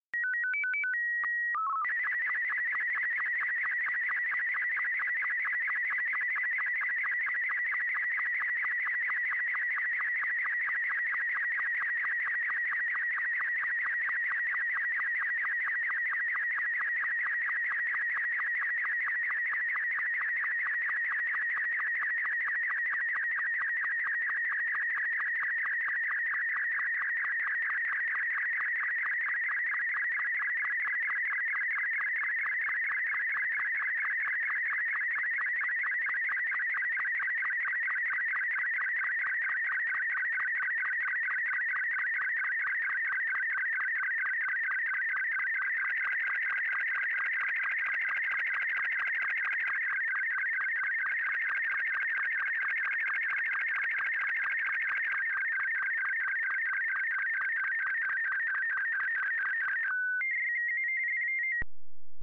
sstv audio ...